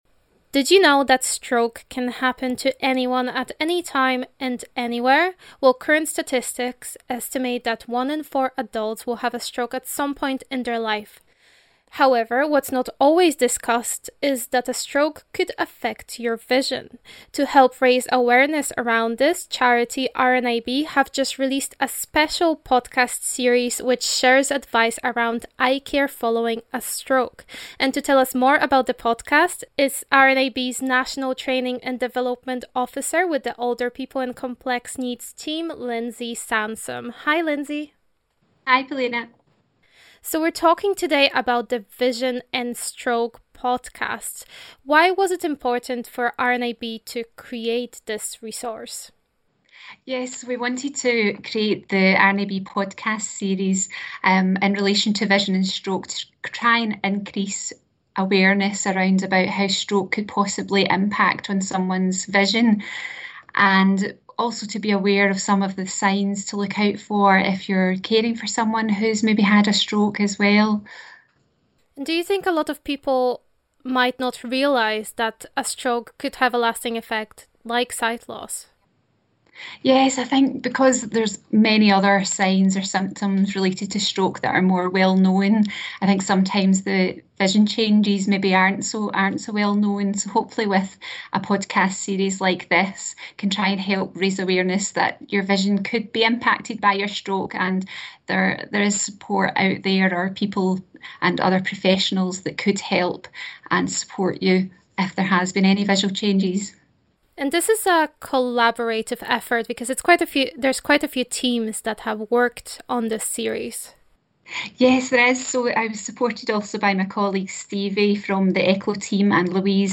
spoke with RNIB Connect Radio about it.